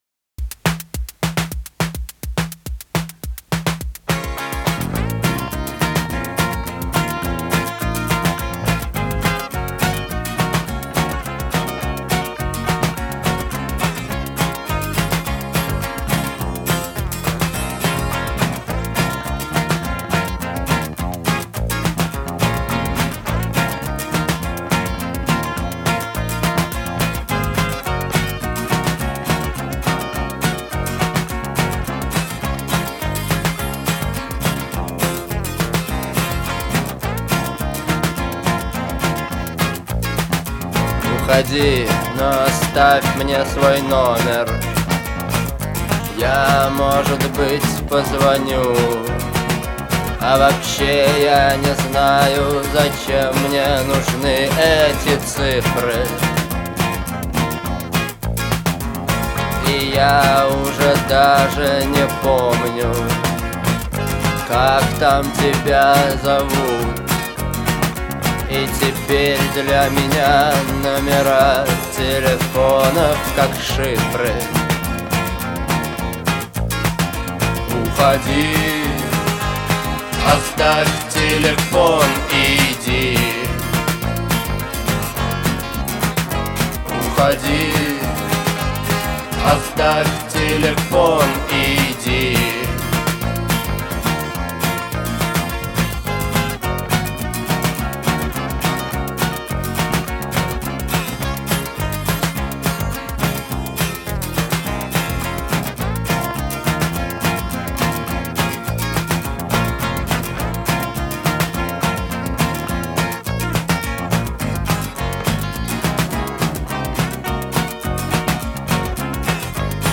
выполненная в жанре рок.